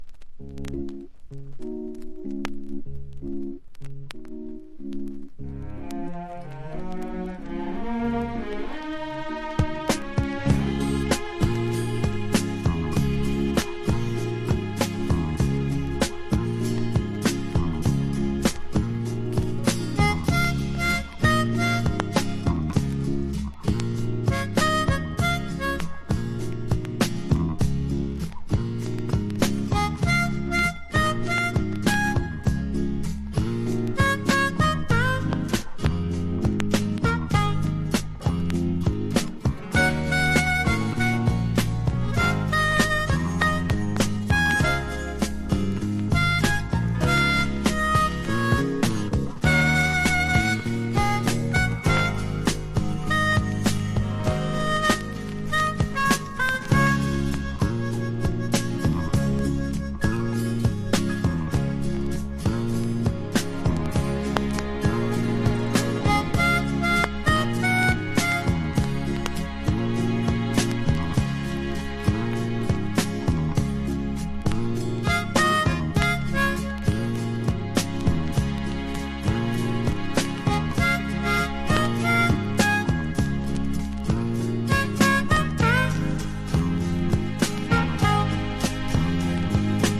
AOR# FREE SOUL